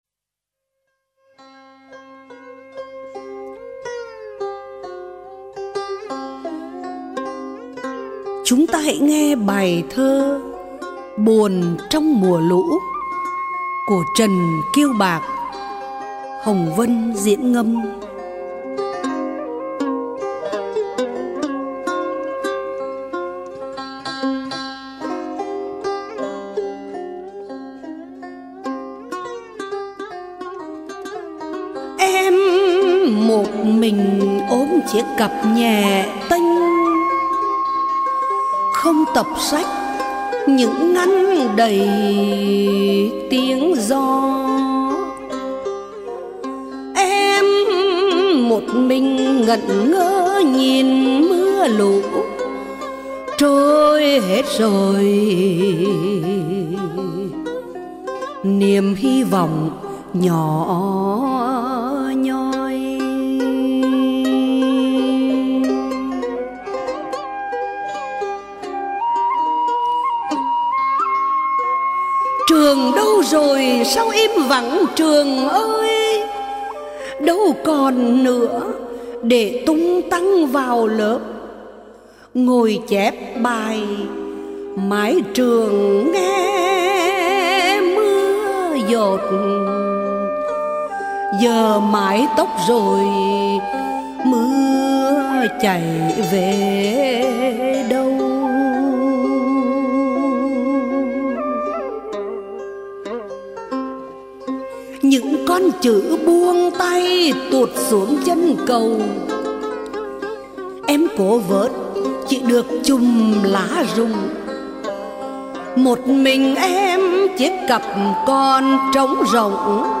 Ngâm Thơ